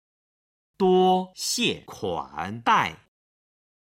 今日の振り返り！中国語発声
01-duoxiekuandai.mp3